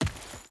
Dirt Chain Run 3.ogg